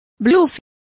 Complete with pronunciation of the translation of bluffs.